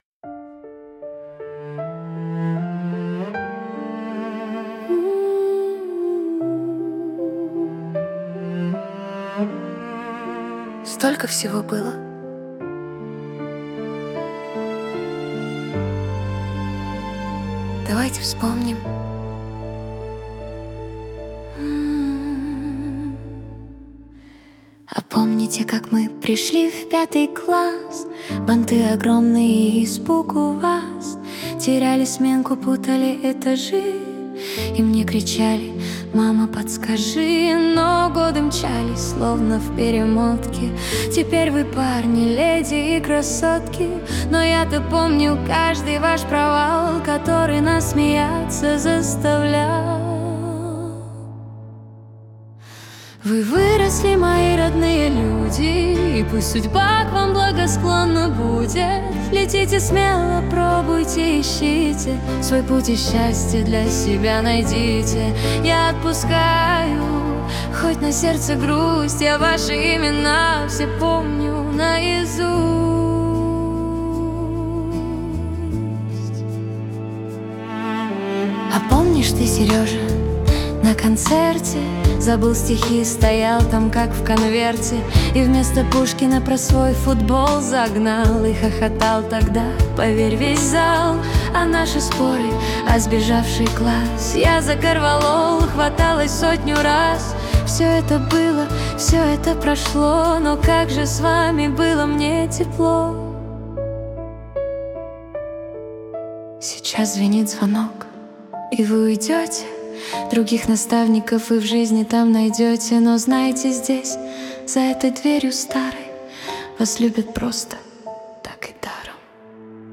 Музыка здесь не перекрикивает слова.
🎻 Инструменты: Рояль, Виолончель Скачать демо
Оркестровый поп
Любовь, исповедь 76 BPM